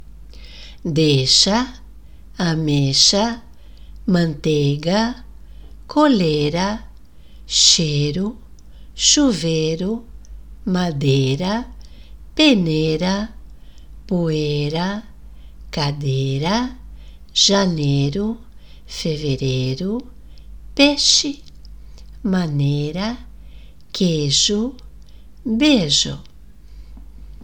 No português do Brasil, é comum “comermos” algumas letras na linguagem oral.